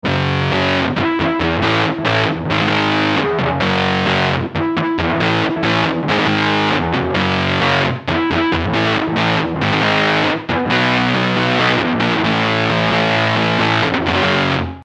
A heavy, thick fuzz with a vintage sound.
guitar - effect - cabinet simulator - sound card (software reverb)